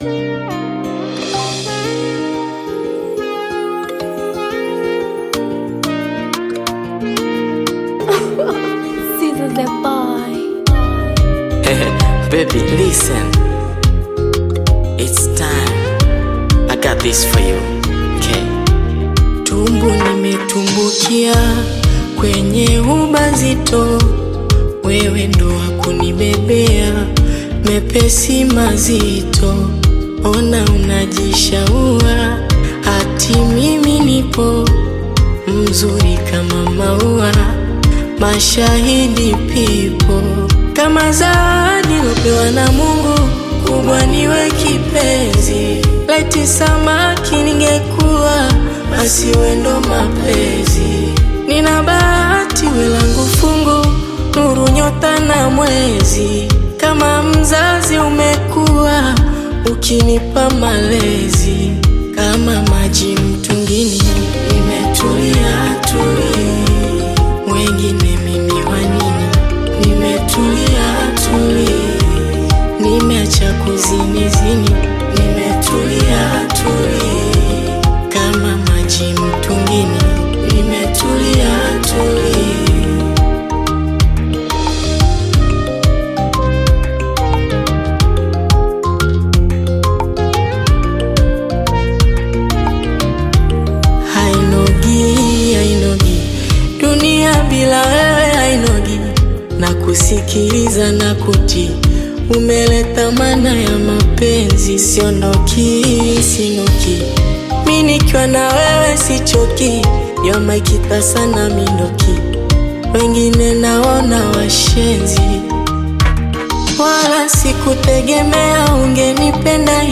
Tanzanian Bongo Flava